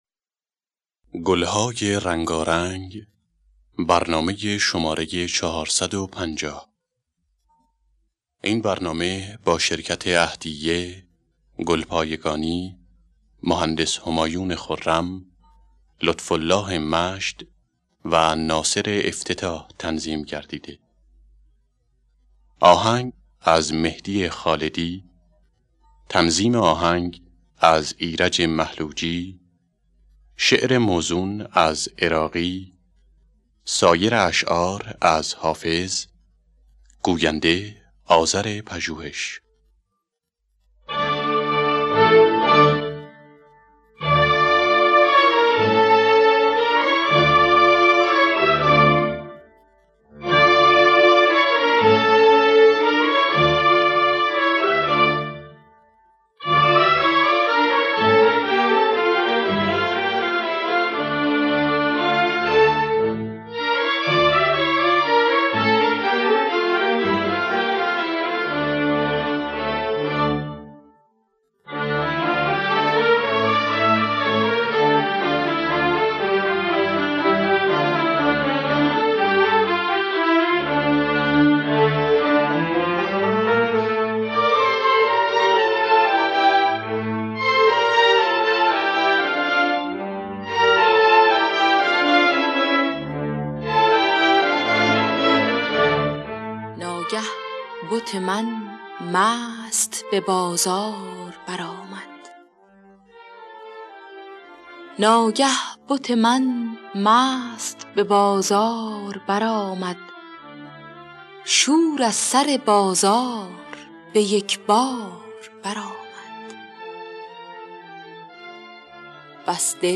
در دستگاه بیات اصفهان